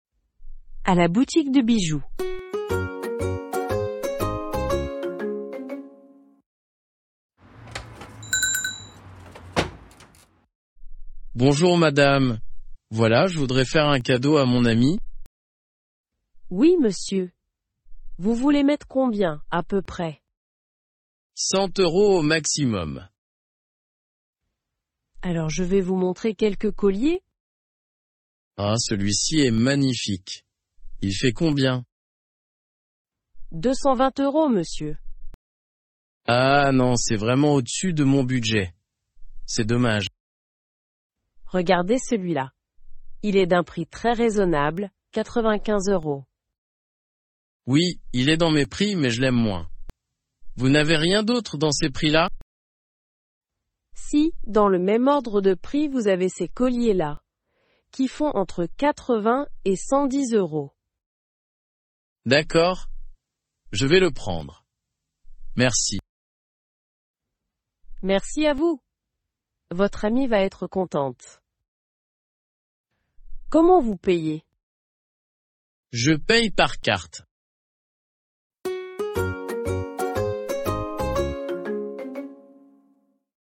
Dialogue à la boutique de bijoux
Dialogues en Français A2